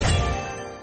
lvup.mp3